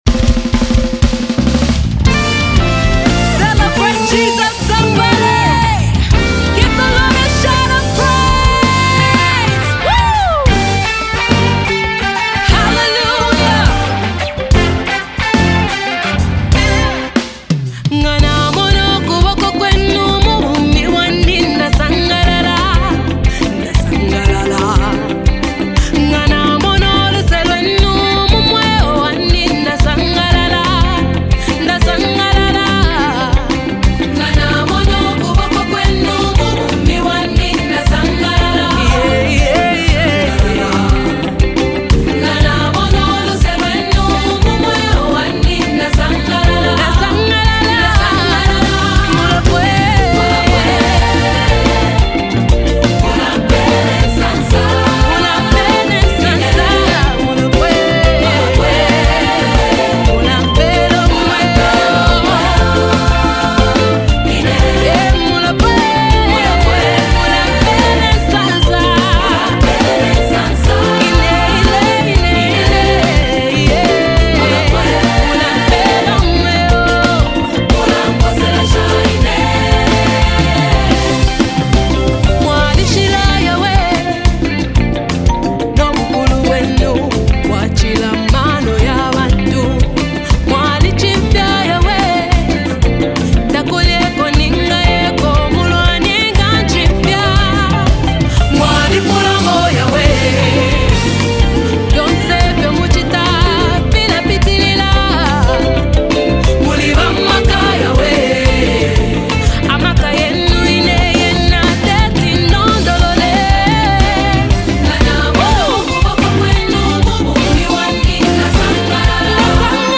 Gospel Music
smooth and melodic vocals